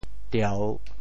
「弔」字用潮州話怎麼說？
弔 部首拼音 部首 弓 总笔划 4 部外笔划 1 普通话 diào 潮州发音 潮州 dieu3 文 中文解释 吊 <動> (吊爲弔的俗字。